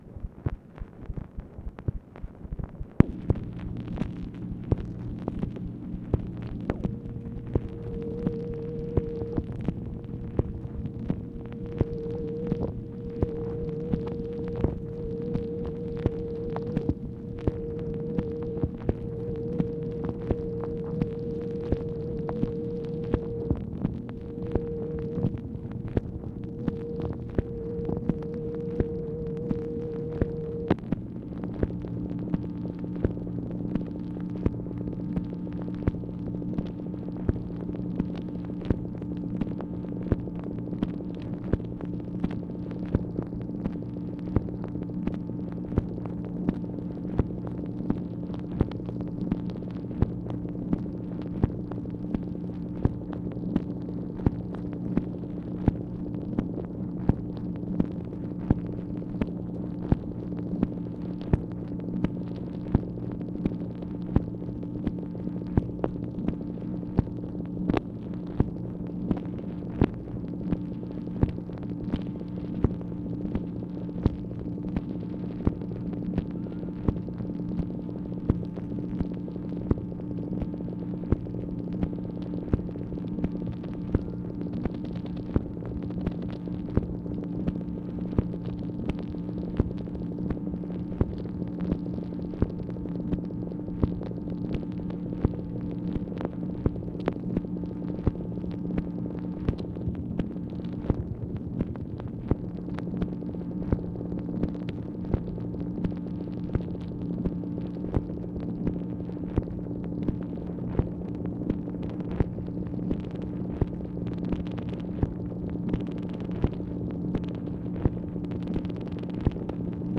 MACHINE NOISE, November 19, 1964